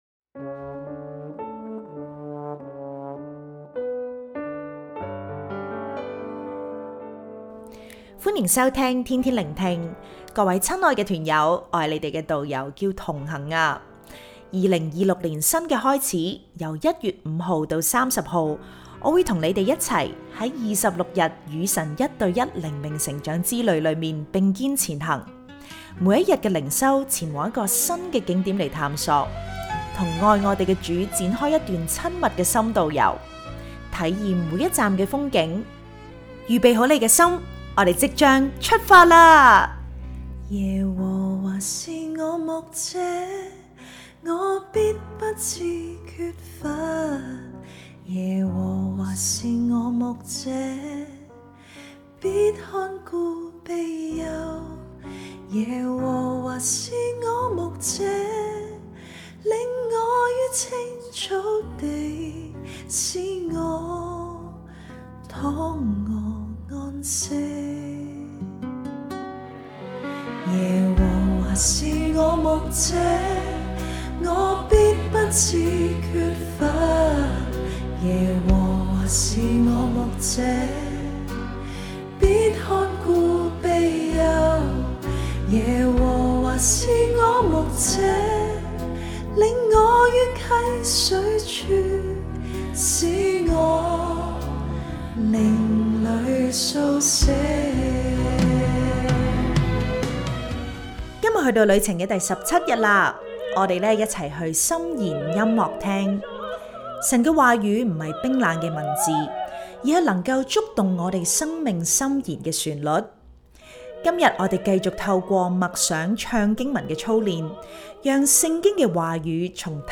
🎶靈修詩歌：《耶和華是我牧者》即興創作Demo